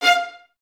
Index of /90_sSampleCDs/Miroslav Vitous - String Ensembles/23 Violins/23 VS Stacc